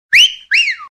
Whistle6.wav